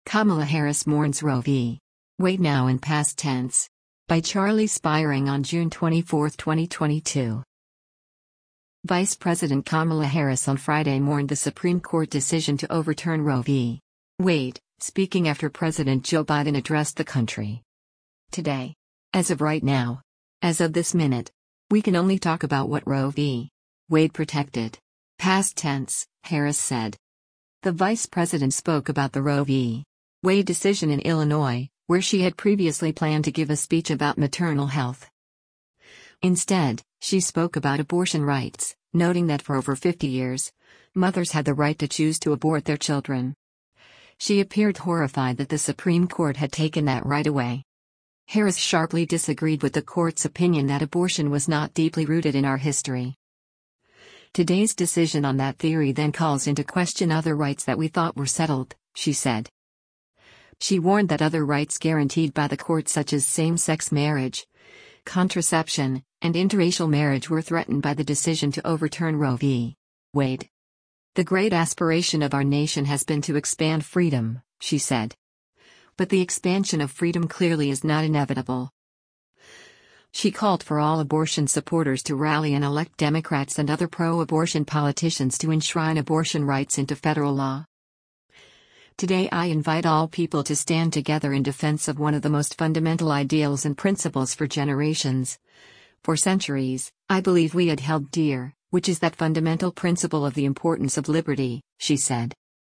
The vice president spoke about the Roe v. Wade decision in Illinois, where she had previously planned to give a speech about maternal health.